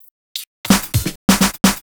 Fill 128 BPM (36).wav